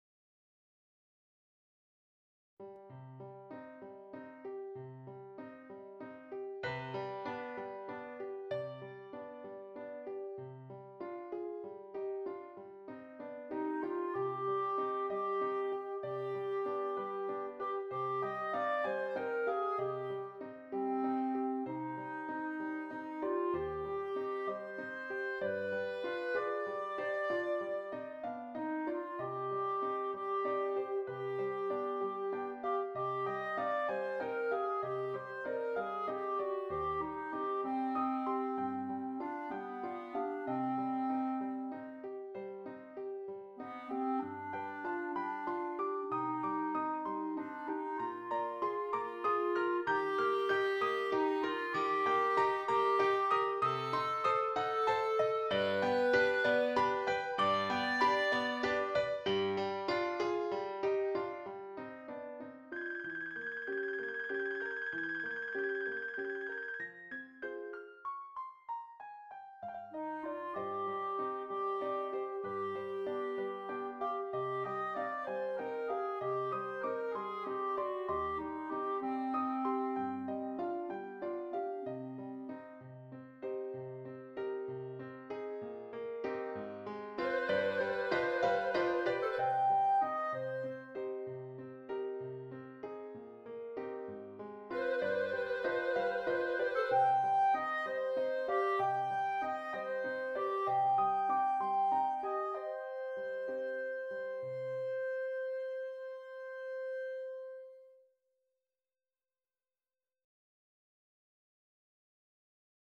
Clarinet and Keyboard